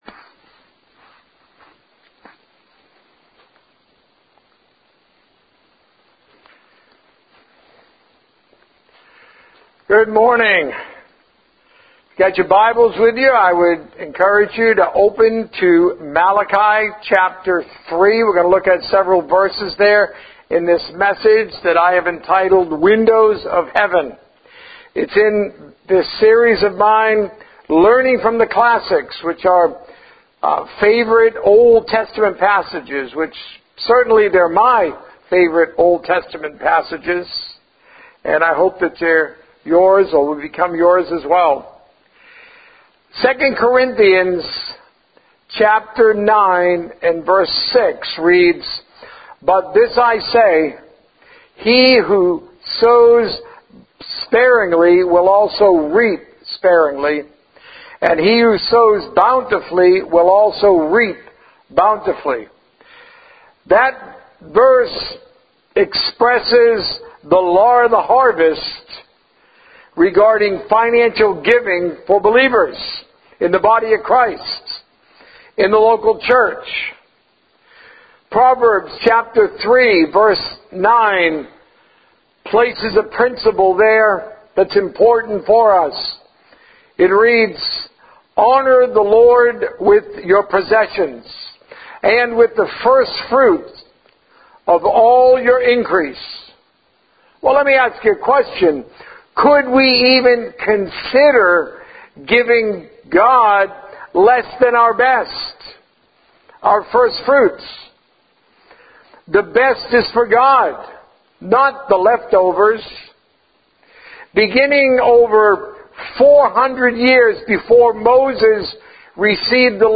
Messages from the sermon on the mount